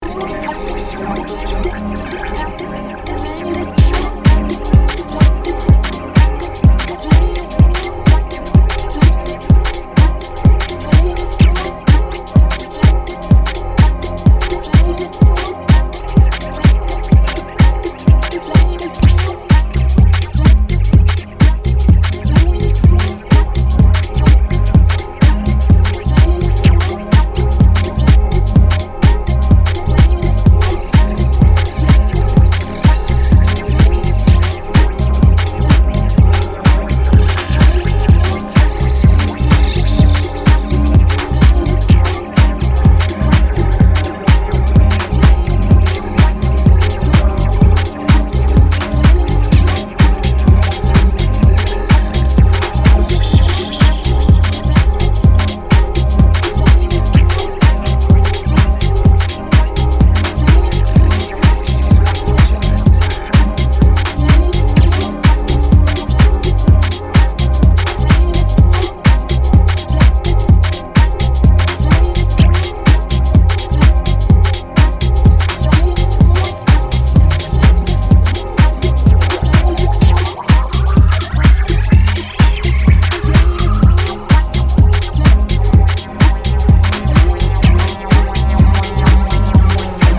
UK Trip Hop group
singer